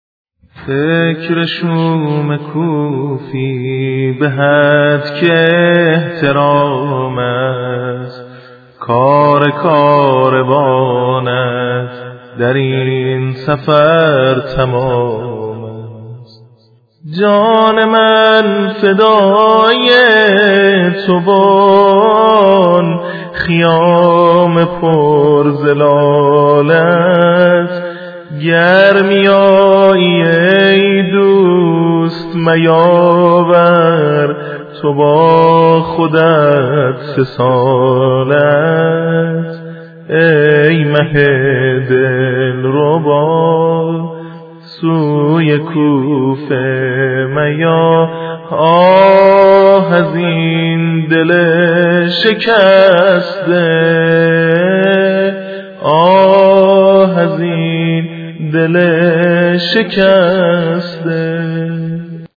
مسلم بن عقیل - - -- - -زمزمه -شهادت- - -